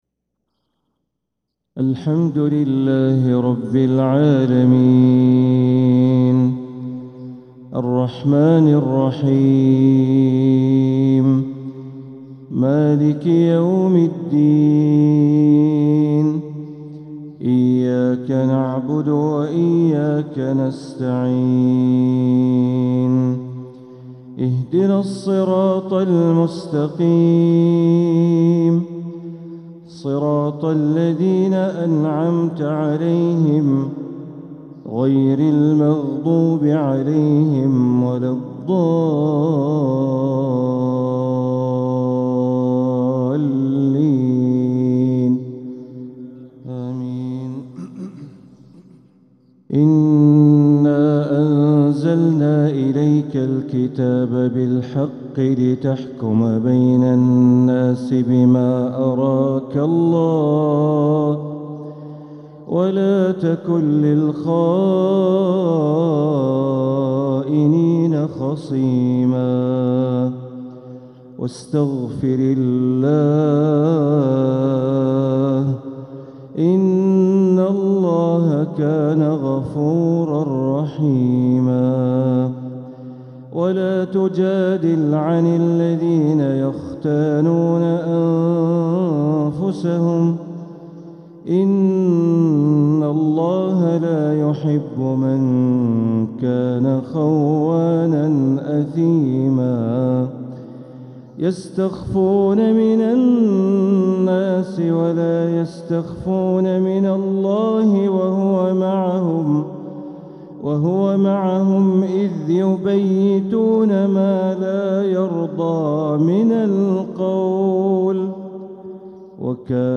تلاوة من سورة النساء ١٠٥-١١٥ | فجر الأحد ١٣ربيع الآخر ١٤٤٧ > 1447هـ > الفروض - تلاوات بندر بليلة